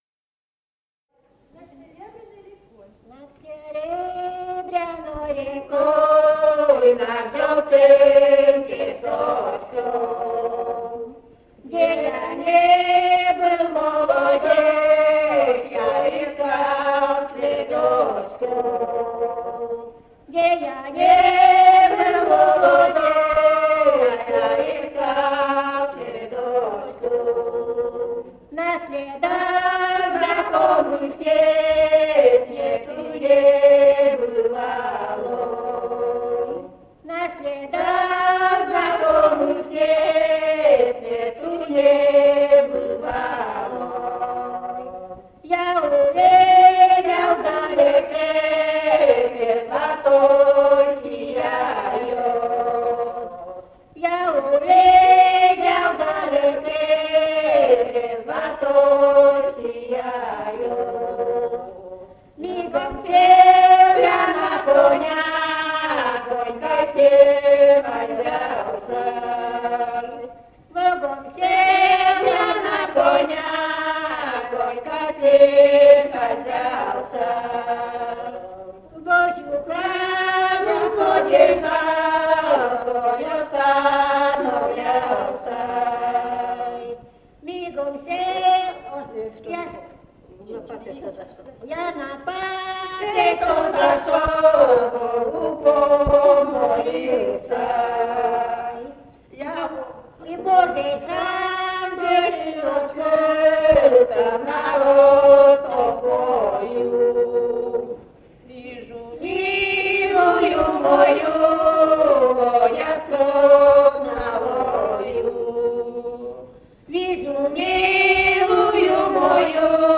«Над серебряной рекой» (лирическая).